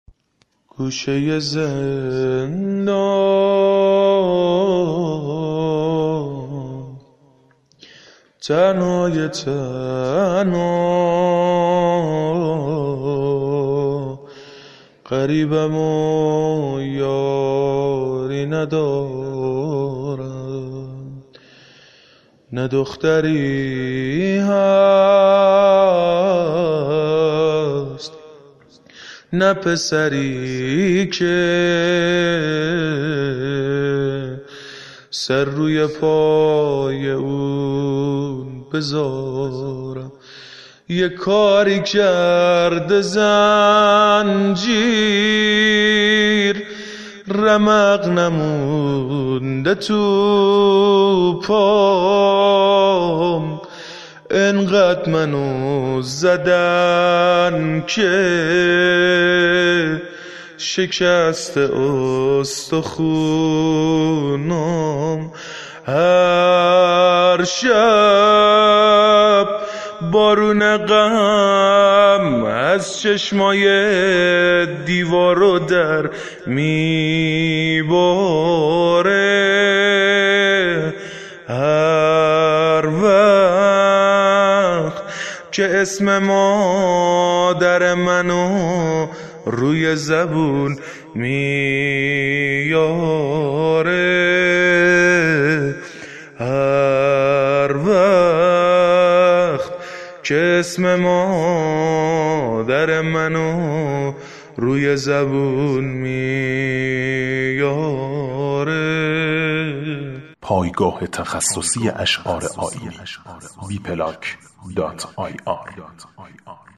واحد ، زمزمه